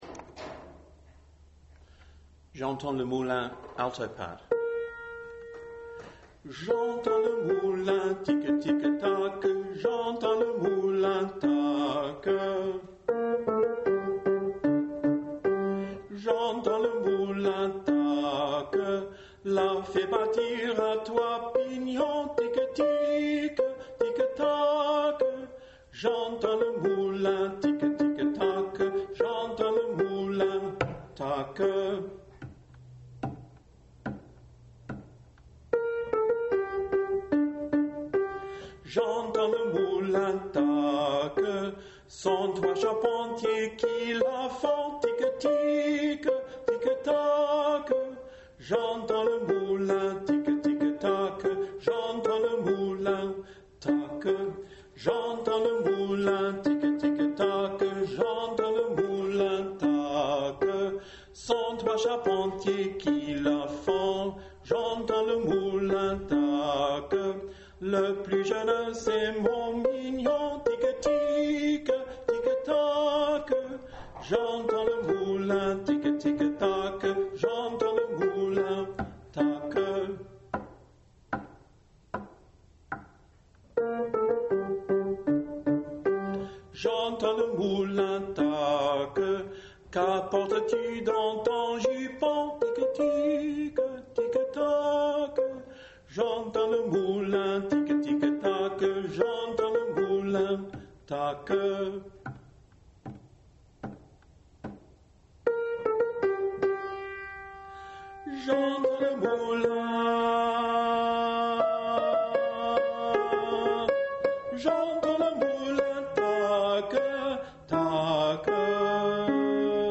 19 PATRIQUIN J’entends Le Moulin ALTO